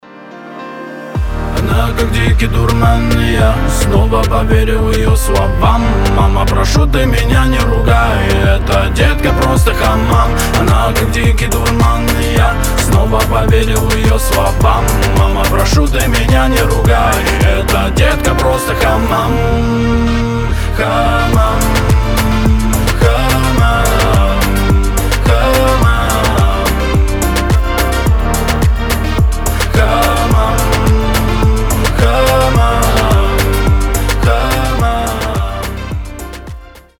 • Качество: 320, Stereo
мужской голос
мелодичные